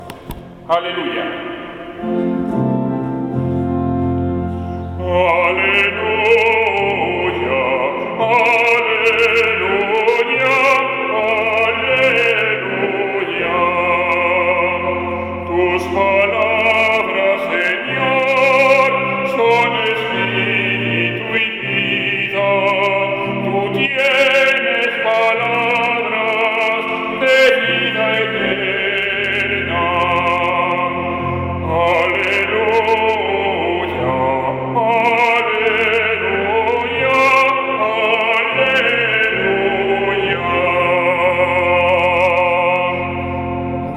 Aleluya. Jn. 6/ 64-69